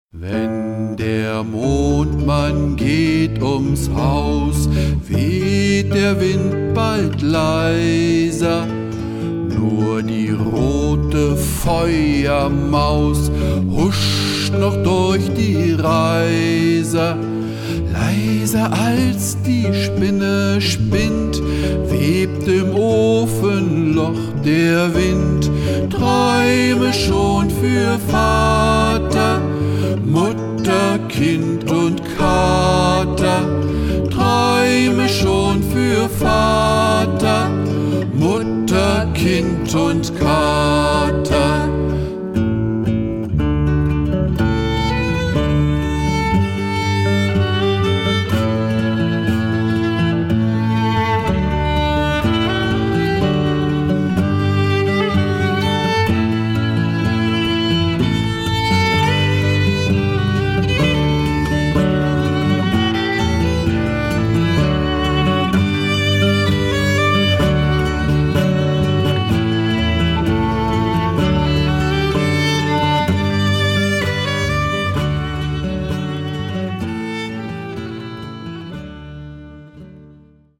Kinderlieder ab 3 Jahren